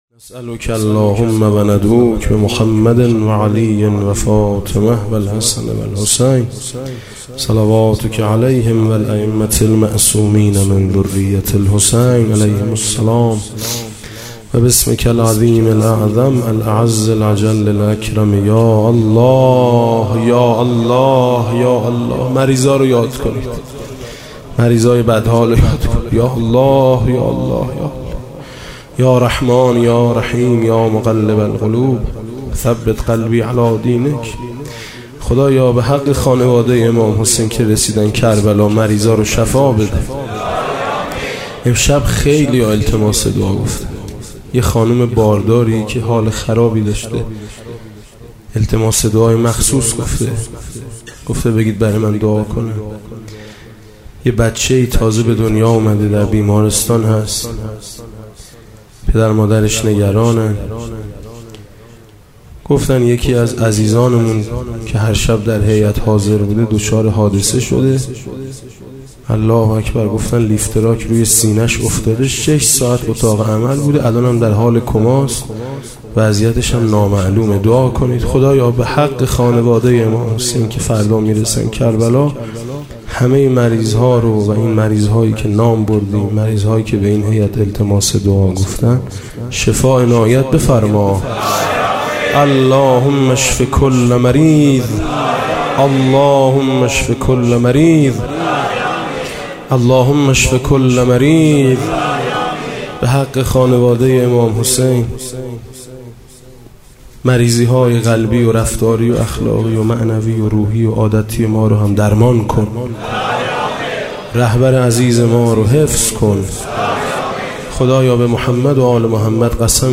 دعا پایان مراسم دوم محرم